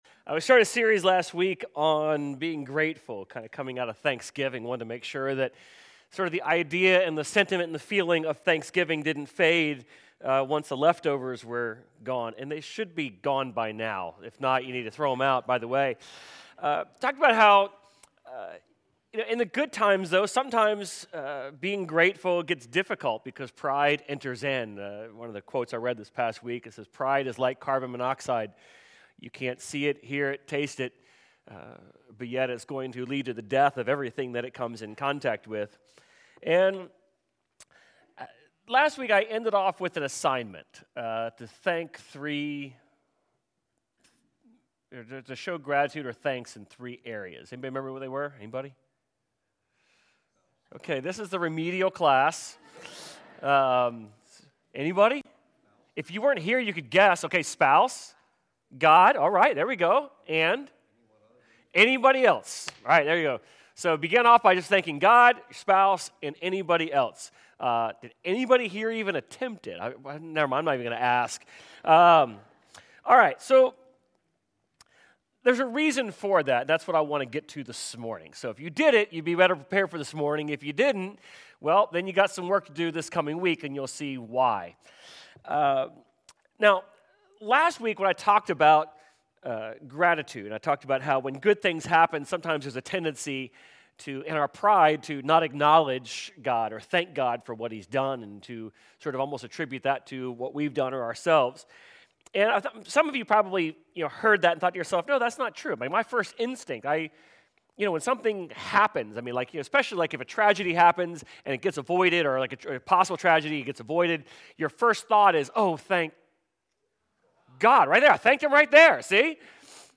Sermon_12.7.25_small.mp3